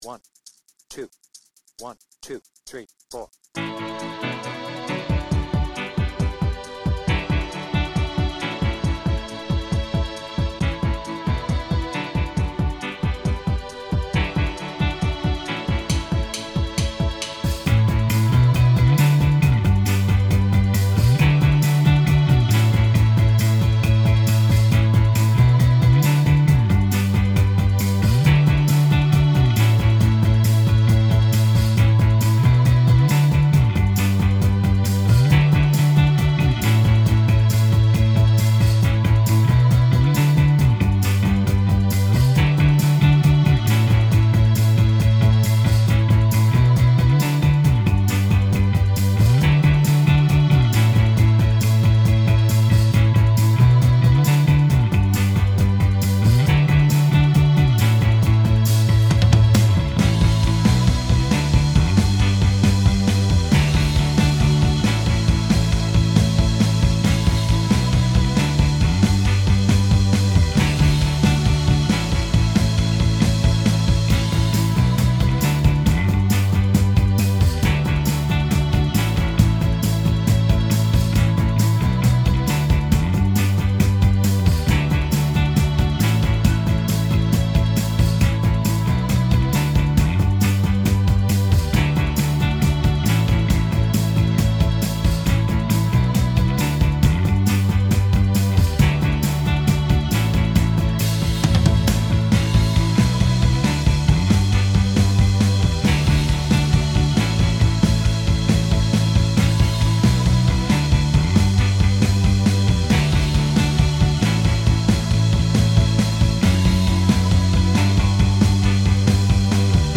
BPM : 136
Without vocals